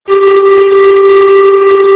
g-tune
snaar3g.amr